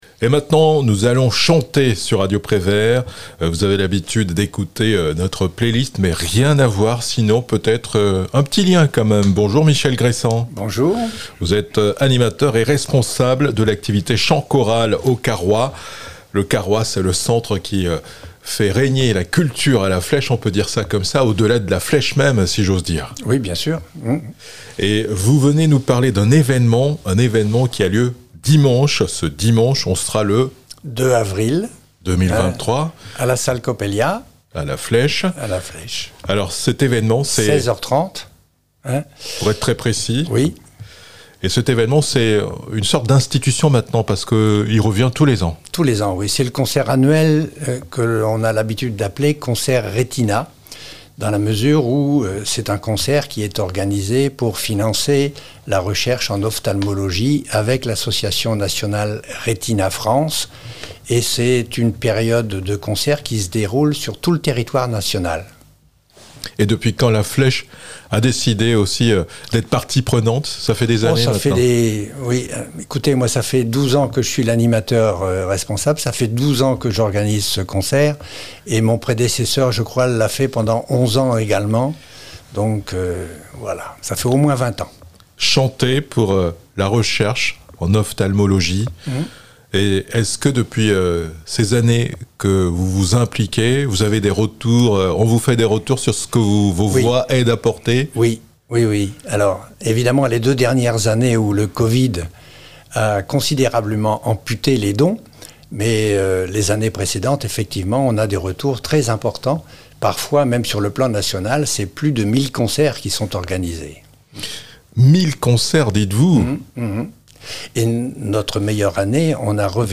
Mille choeurs pour un regard : concert à La Flèche pour Retina France